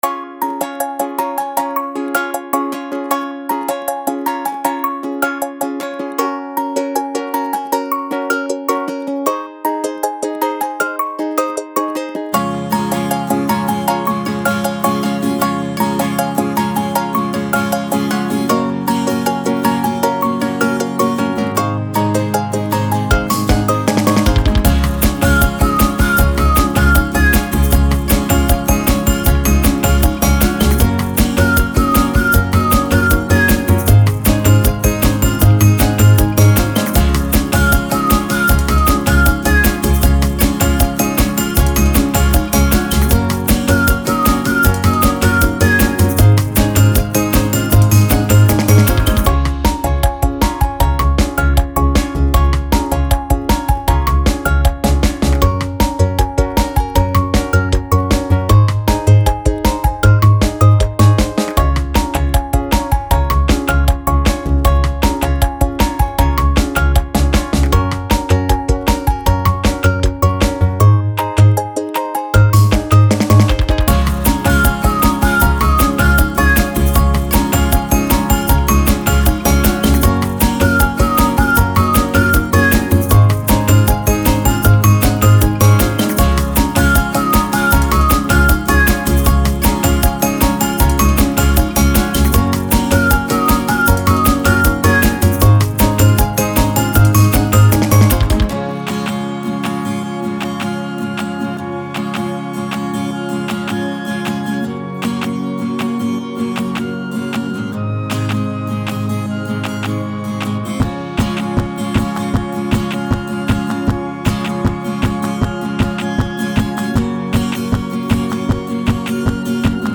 Fresh, fun, folky tunes for feelgood types